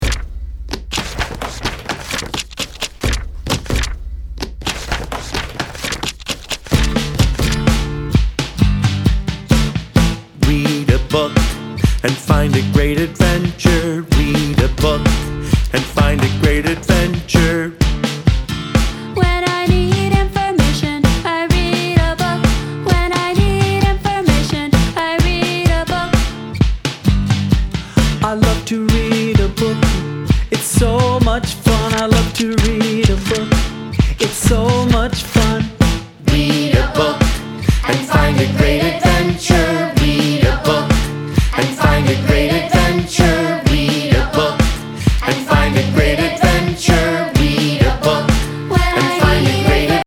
3-part converging song